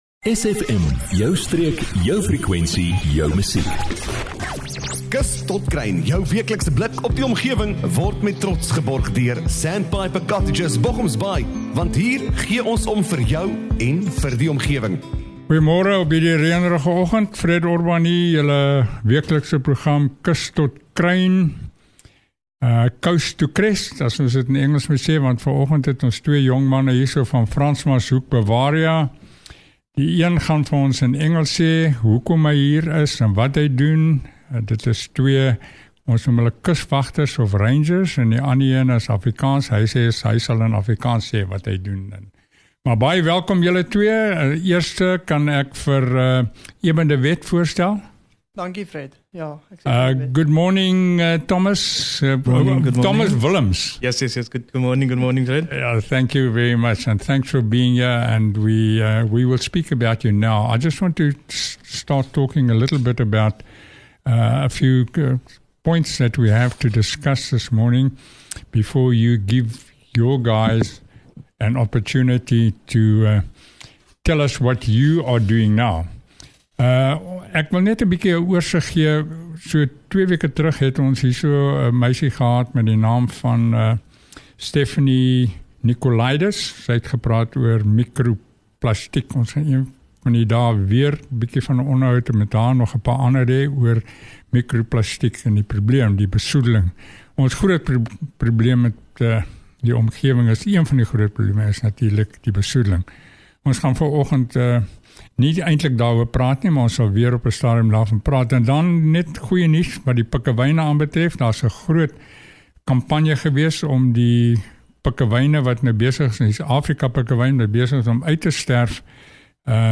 kuier in die ateljee